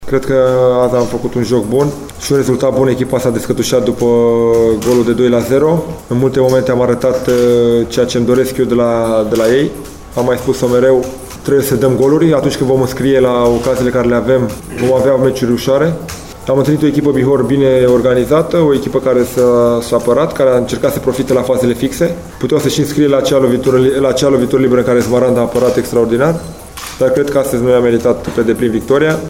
În fişierele alăturate puteţi asculta reacţiile celor doi tehinicieni